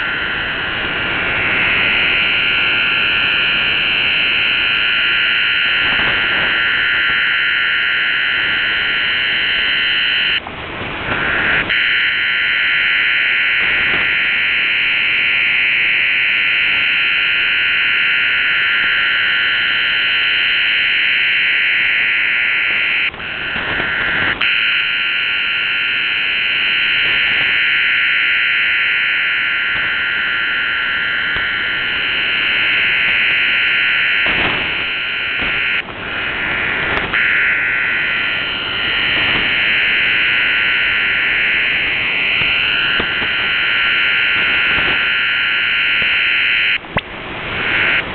Начало » Записи » Радиоcигналы на опознание и анализ
OFDM-16 (предположительно)